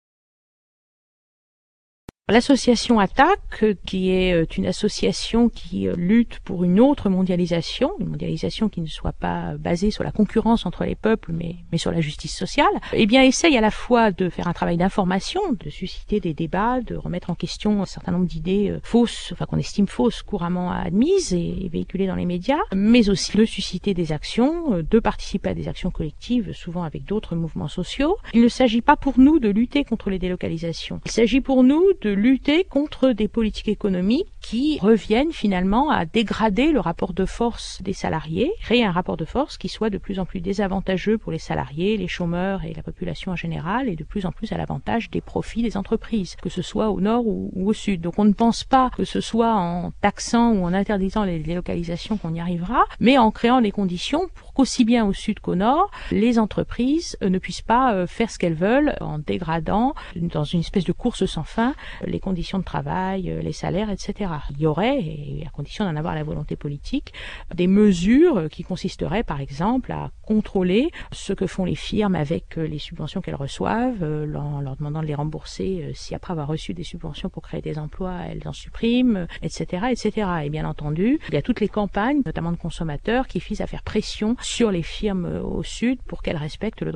Cet interview a été réalisé à l’issue de la projection du documentaire de Marie France Collard, Ouvrières du monde, (Belgique, 2000. 52’).